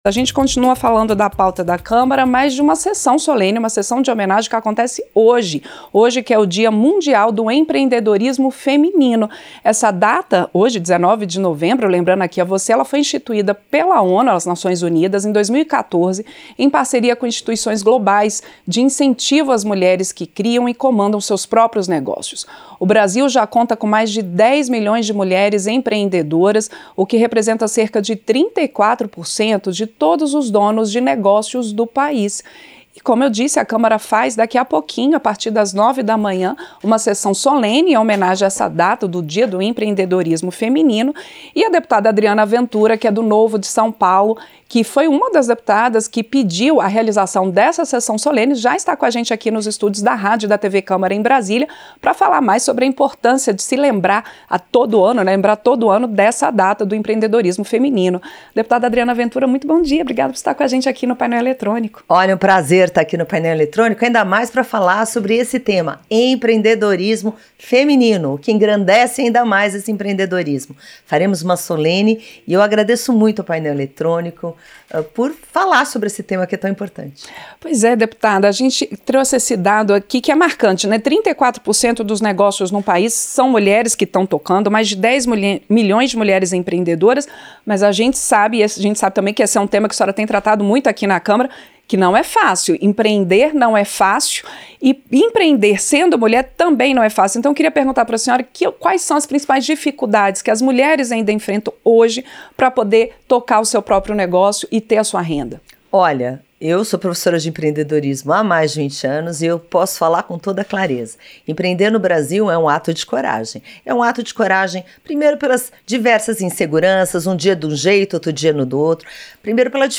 Entrevista - Dep. Adriana Ventura (Novo-SP)